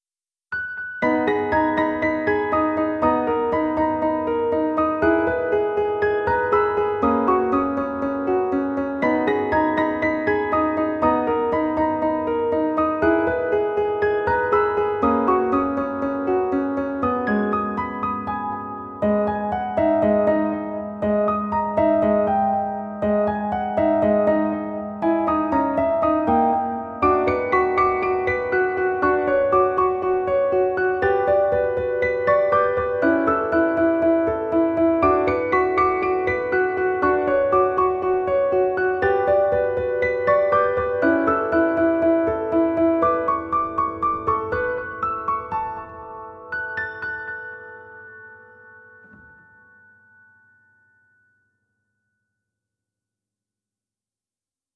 PIANO Q-S (31)